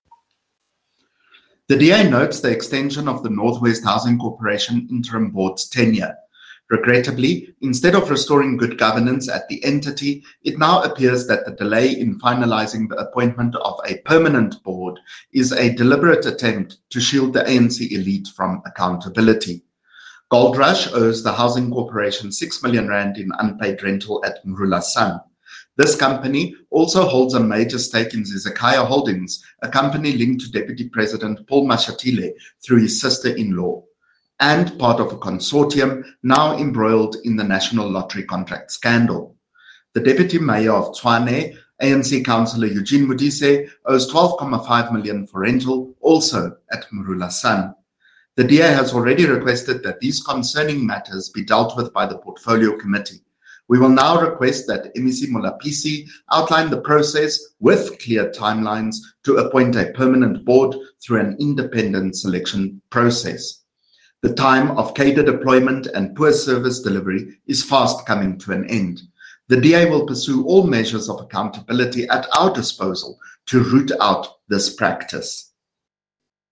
Note to Broadcasters: Please find linked soundbites in
English and Afrikaans by CJ Steyl MPL.